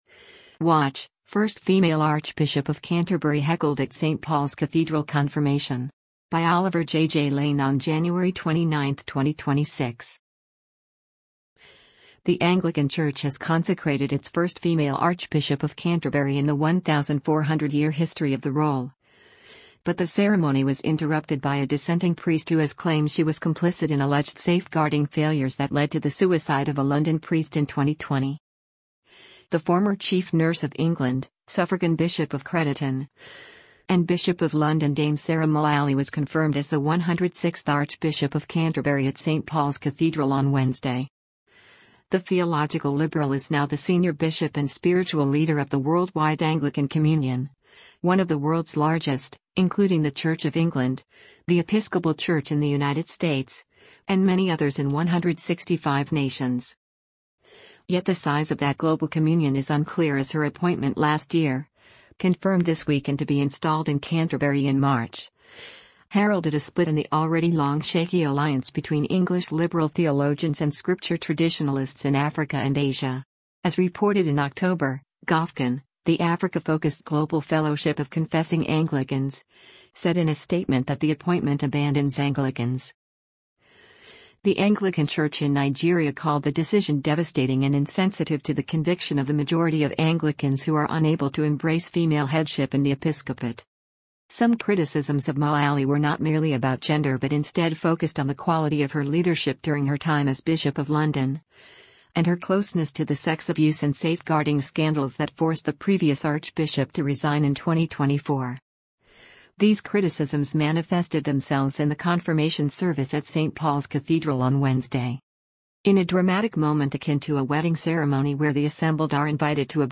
In response to this, a priest in the cathedral shouted out “I did”, and was quickly bundled out of the building by vergers.